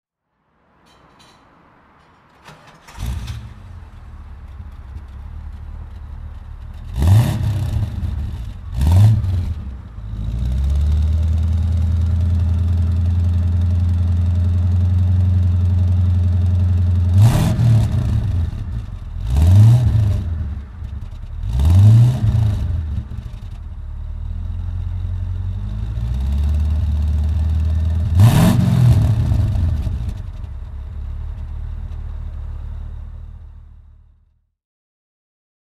Motorsounds und Tonaufnahmen zu Rolls-Royce Fahrzeugen (zufällige Auswahl)
Rolls-Royce Camargue (1979) - Starten und Leerlauf